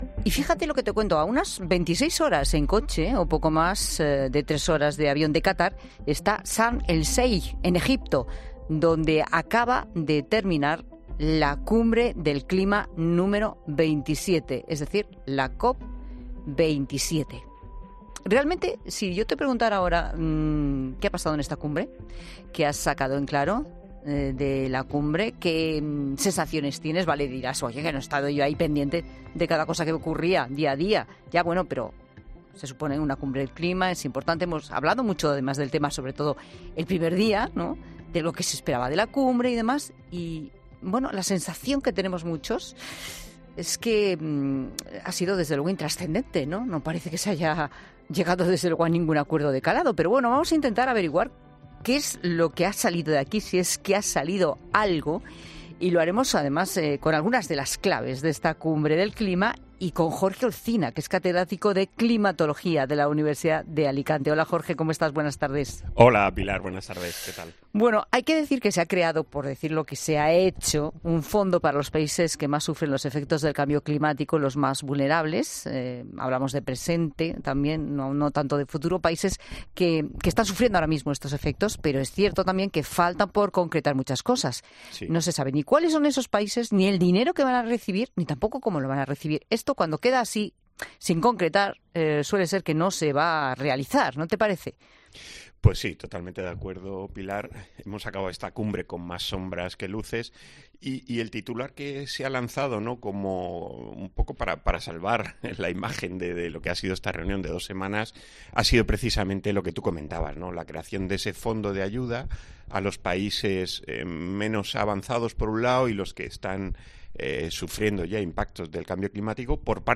Un experto explica a COPE el acuerdo de la Cumbre del Clima: “No hay un resultado concreto”